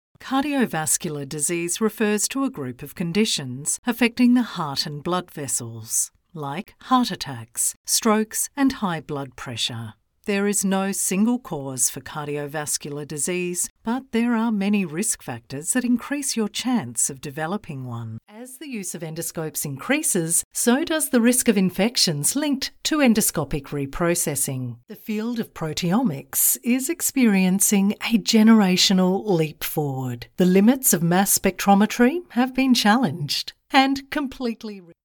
Englisch (Australisch)
IVR